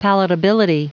Prononciation du mot palatability en anglais (fichier audio)